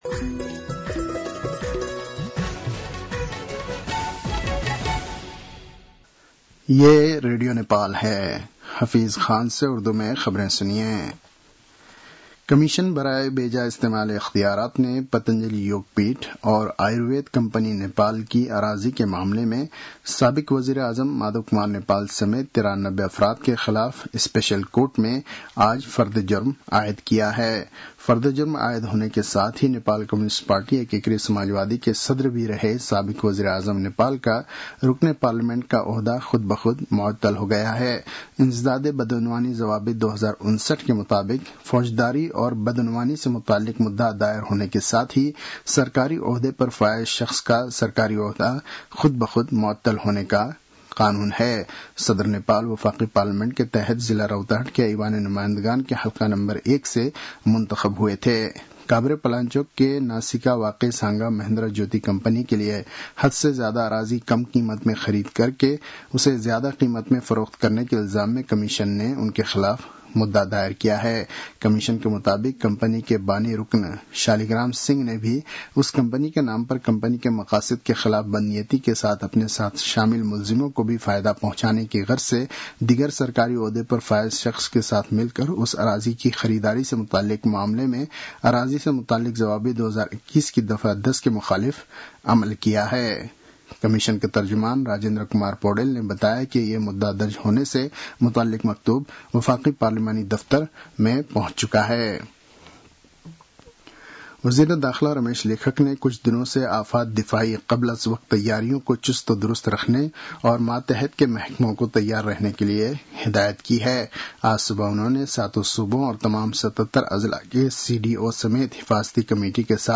उर्दु भाषामा समाचार : २२ जेठ , २०८२
Urdu-news-2-22.mp3